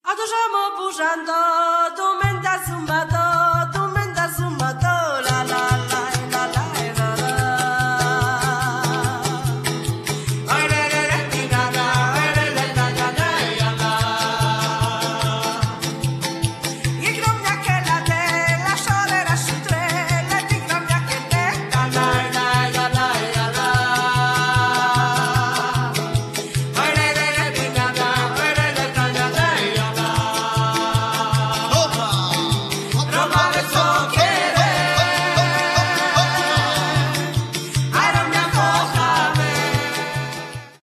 jak też pieśni cygańskie